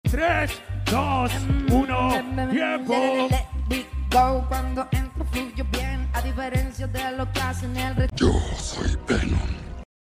TE SALUDO CON LA VOZ DE VENOM